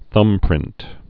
(thŭmprĭnt)